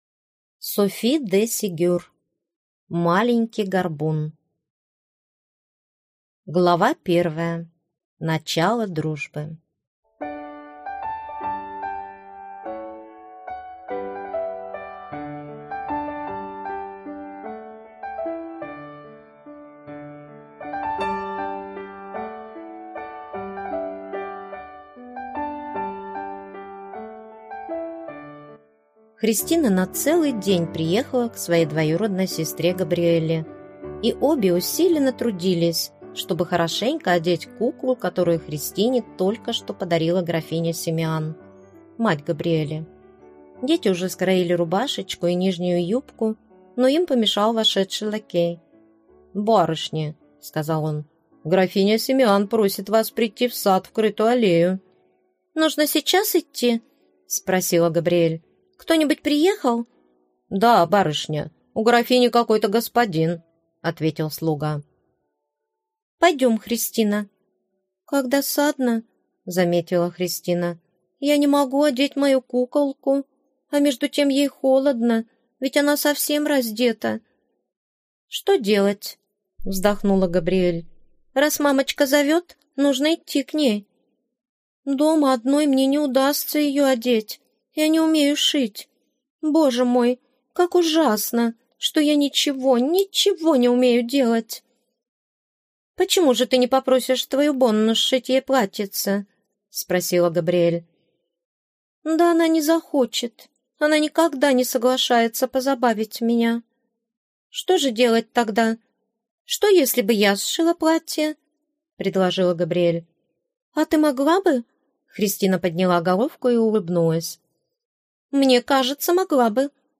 Аудиокнига Маленький горбун | Библиотека аудиокниг